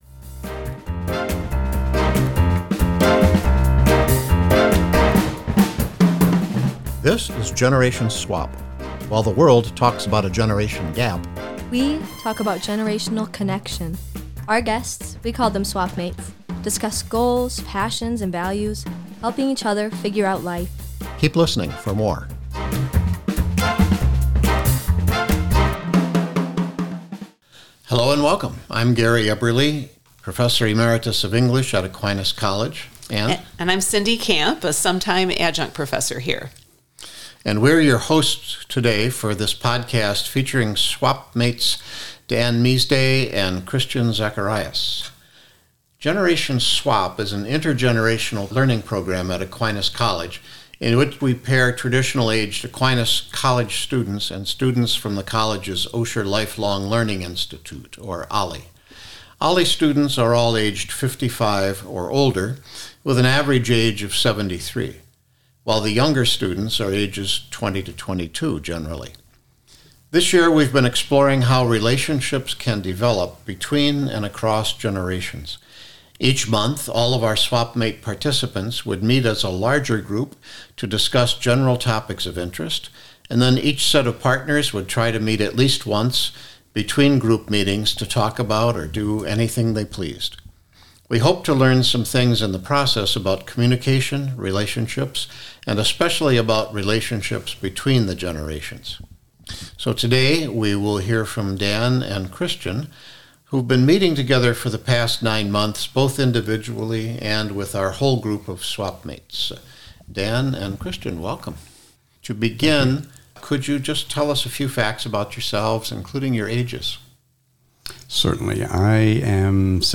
The course capstone project was a Generation Swap podcast interview exploring how generational differences can be a source of strength and growth.